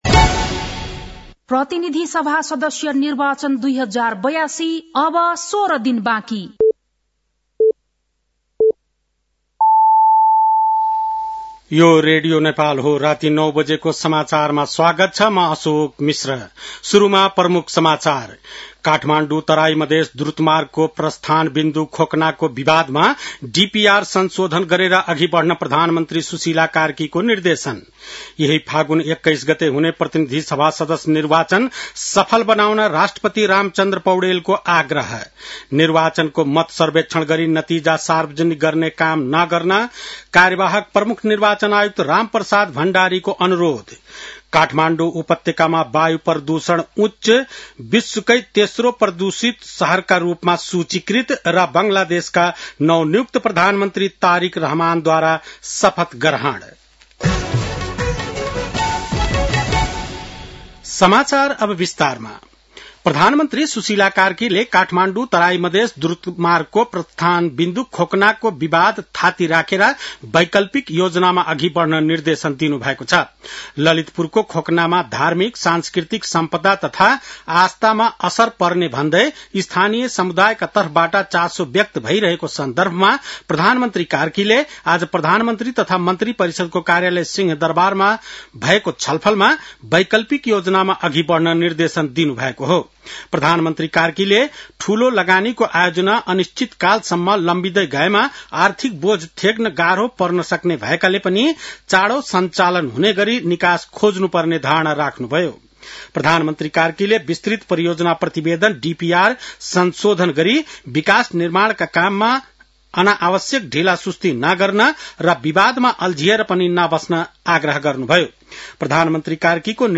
बेलुकी ९ बजेको नेपाली समाचार : ५ फागुन , २०८२
9-pm-nepali-news-11-5.mp3